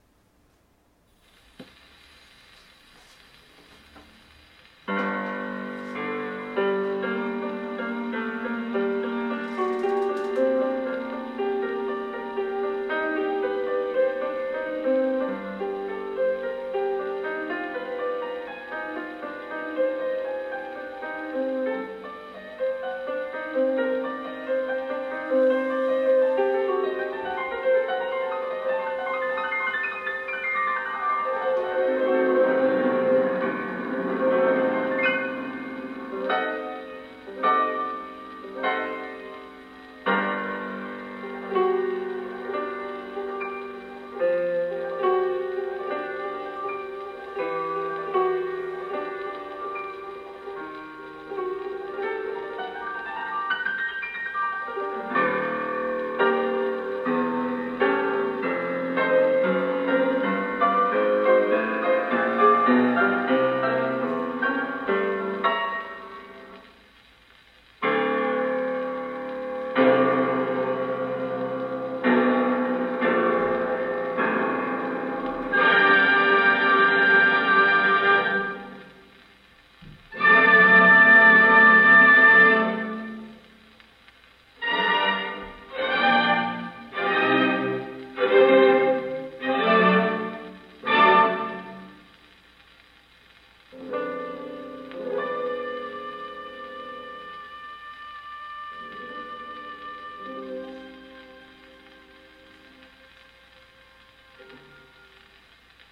ベンノ・モイセイビッチ(P:1890-1963)
※このサンプルは蓄音機HMV163で演奏したものです。